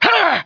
monsters / plagueElf / attack2.wav
attack2.wav